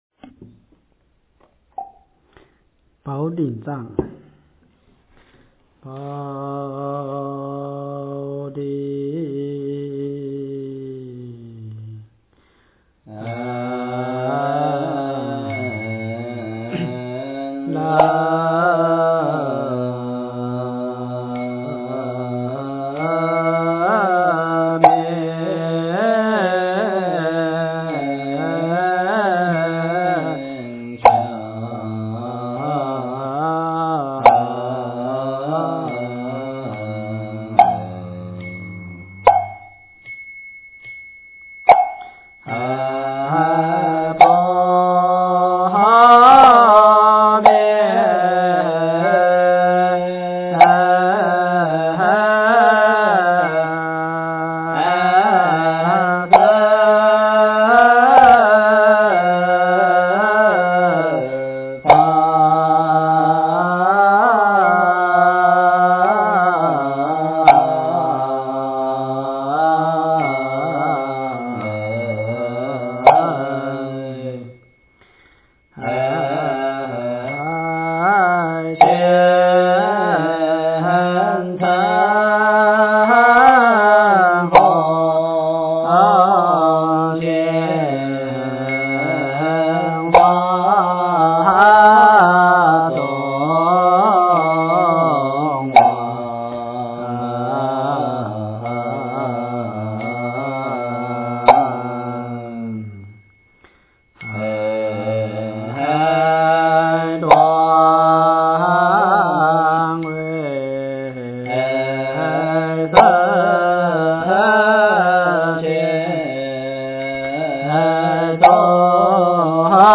经忏
佛音 经忏 佛教音乐 返回列表 上一篇： 六字大明咒--佛光山梵呗团 下一篇： 一心求忏悔-闽南语--新韵传音 相关文章 文殊菩萨盛名咒-唱颂版--未知 文殊菩萨盛名咒-唱颂版--未知...